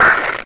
scrump_hurt.ogg